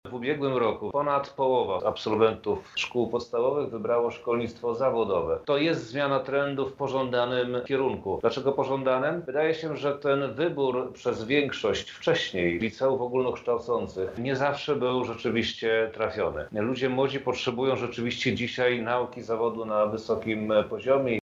Jeszcze kilka lat temu większość większość absolwentów szkół podstawowych wybierała licea ogólnokształcące, a nie szkolnictwo zawodowe – mówi Minister Edukacji i Nauki Przemysław Czarnek: